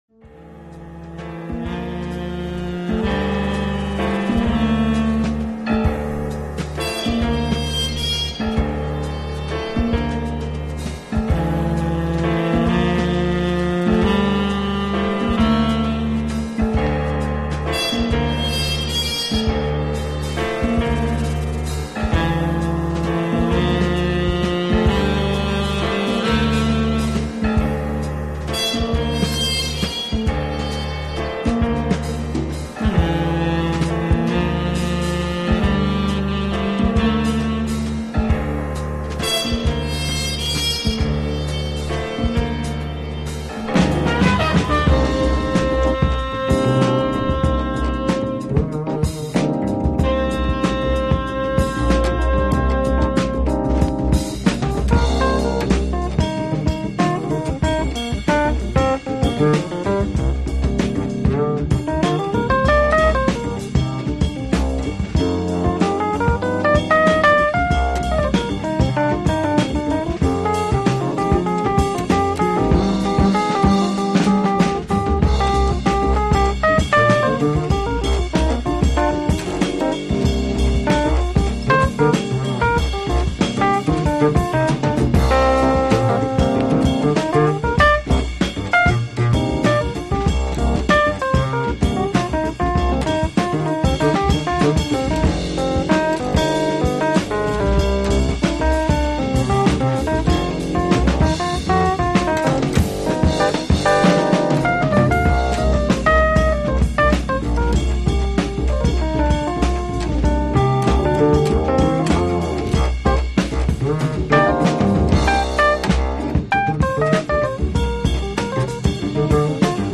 Includes a nice jazz tune with piano
the great jazzy groove
with perfect fender rhodes solo.